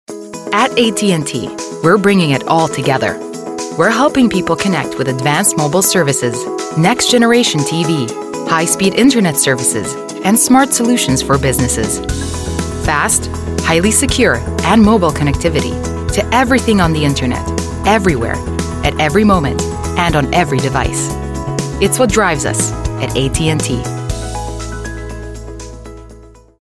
Native speaker Female 20-30 lat
Nagranie lektorskie